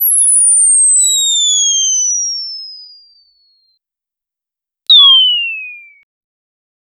very rare high rarity item drop sound effect for video game. dopamine inducing and bombastic